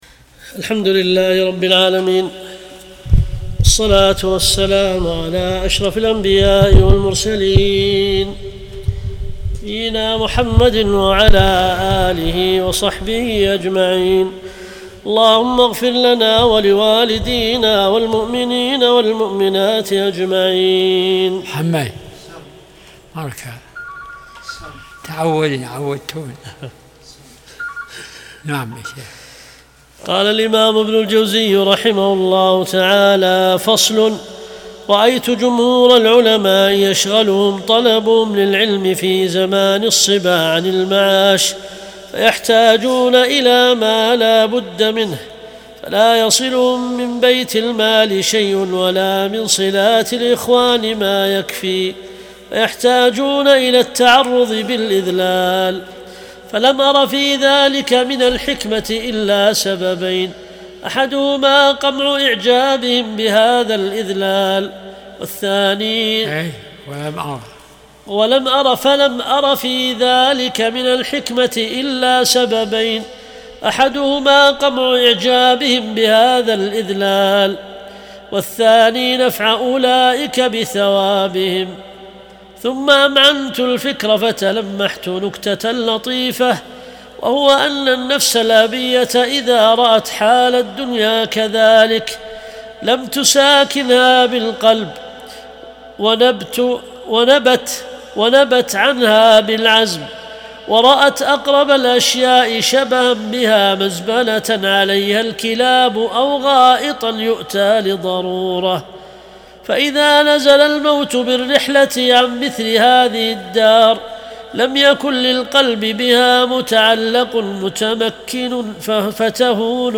درس الأربعاء 50